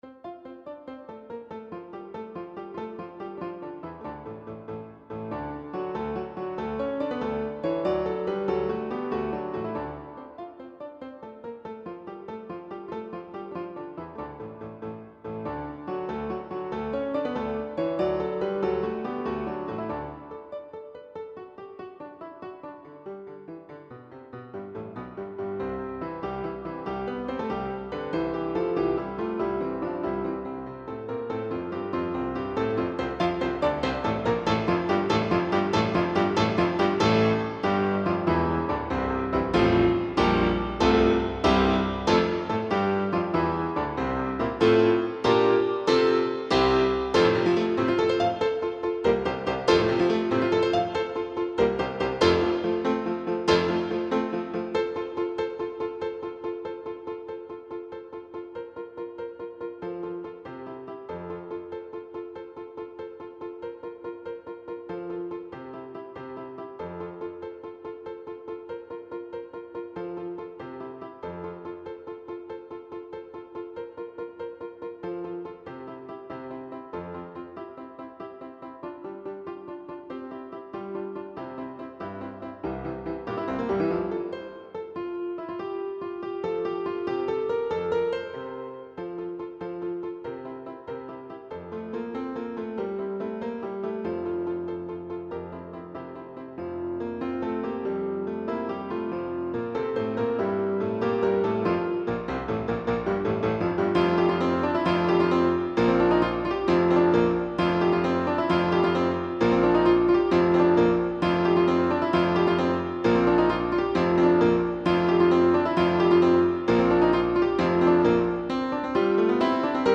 Without Pianist 1